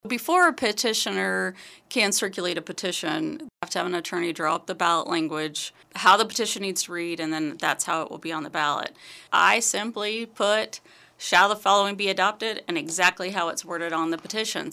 Saline County Clerk Jamie Doss joined in on the KSAL Morning News Extra to talk about the process ahead of the vote. She says the ballot will simply read as the petition did.